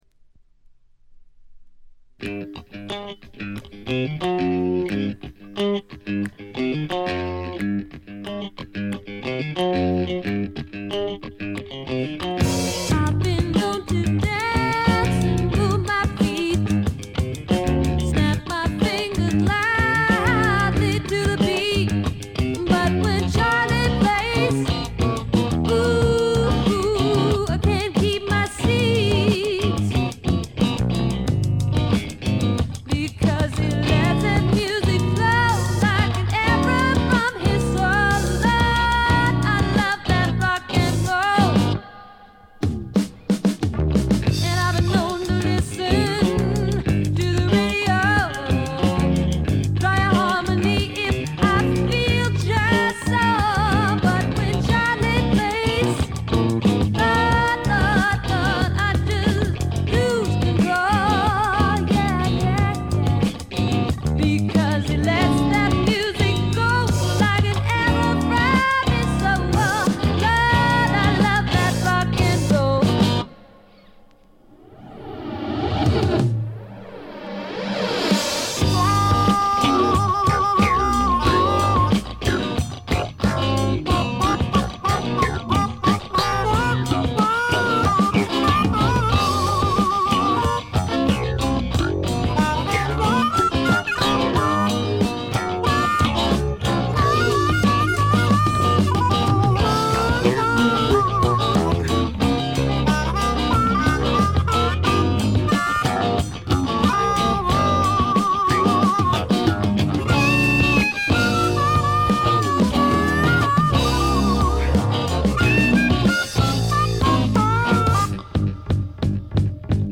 わずかなノイズ感のみ。
気を取り直して・・・これはシアトル産の自主制作盤で、知られざるAOR系女性シンガーソングライターの快作です。
試聴曲は現品からの取り込み音源です。
Recorded At - Sea-West Studios, Seattle